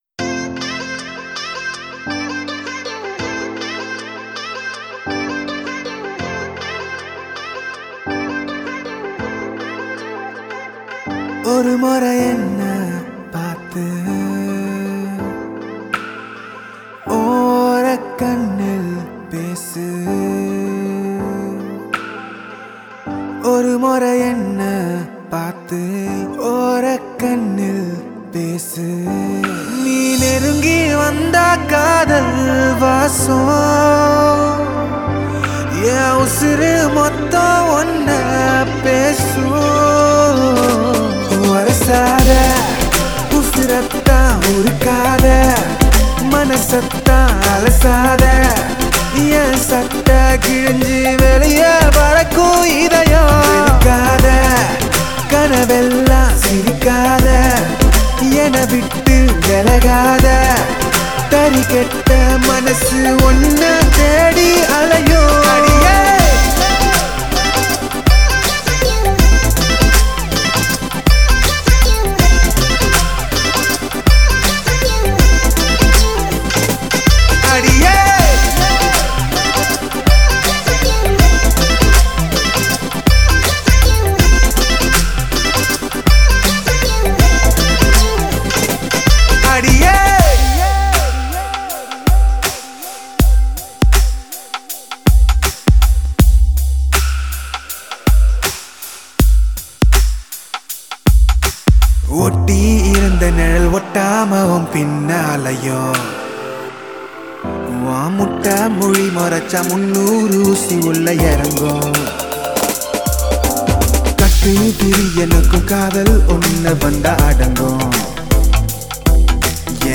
soulful and feel-good Tamil romantic track
soft melody, cute rural-flavoured vibe